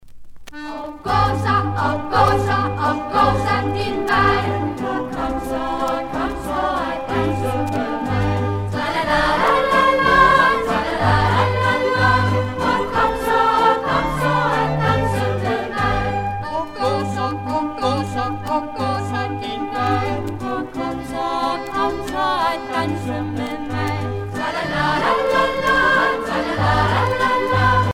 Danske folkesange
Pièce musicale éditée